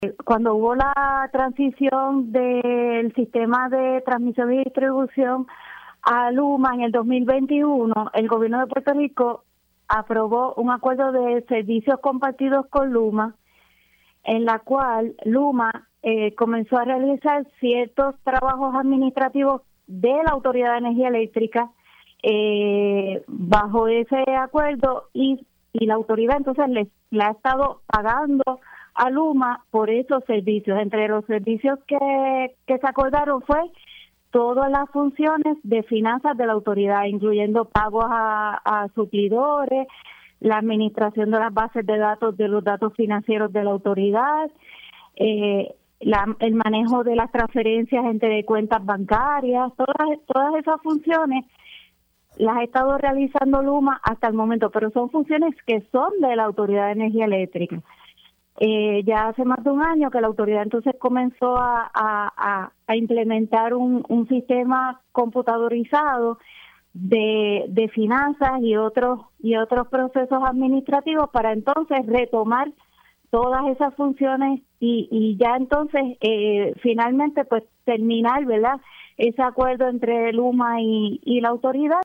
La ingeniera Zapata informó que la AEE busca retomar los controles de las finanzas y servicios administrativos que fueron cedidos a LUMA durante la transición del sistema energético.